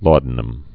(lôdn-əm)